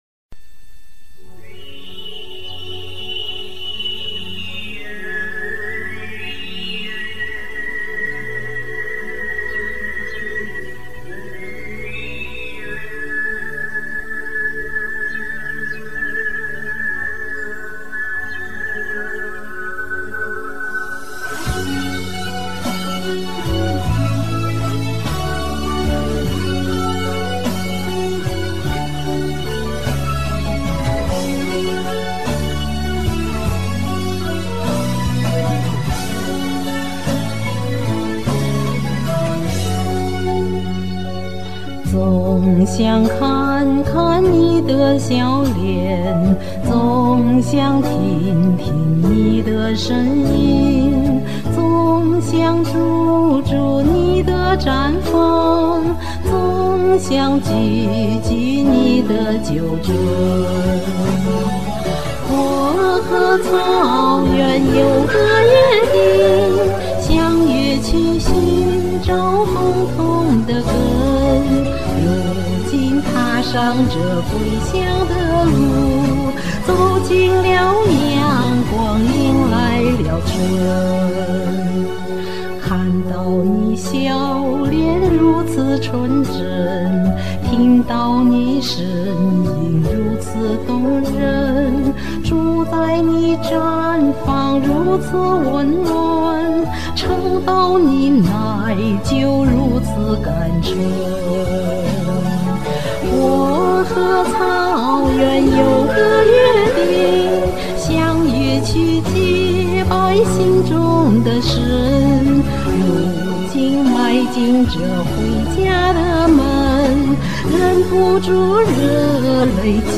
悠扬动听草原的歌曲！
靓丽好声音！